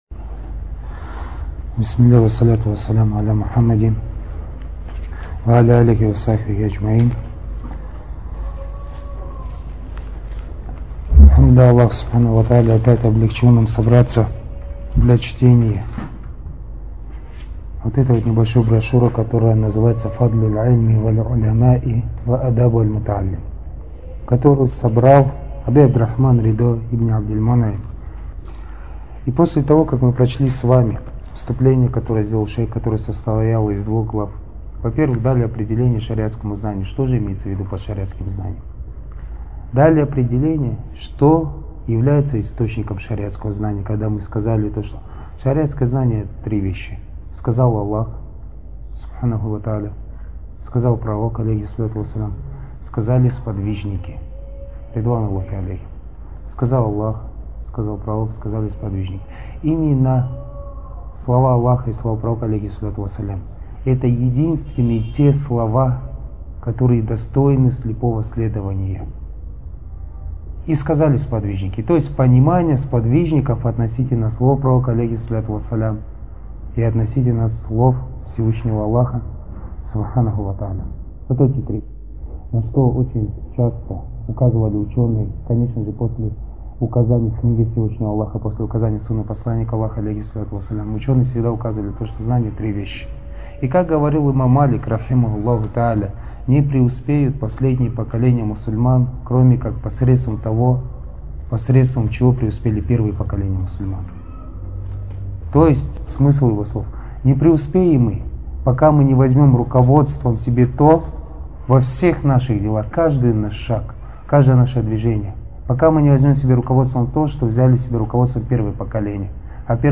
Содержание: лекция о вреде зависти